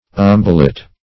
umbellet - definition of umbellet - synonyms, pronunciation, spelling from Free Dictionary Search Result for " umbellet" : The Collaborative International Dictionary of English v.0.48: Umbellet \Um"bel*let\, n. (Bot.) A small or partial umbel; an umbellule.